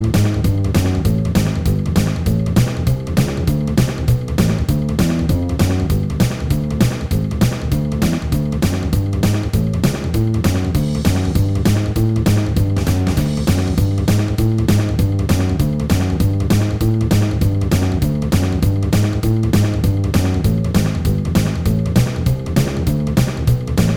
Minus Lead Guitar Rock 'n' Roll 3:28 Buy £1.50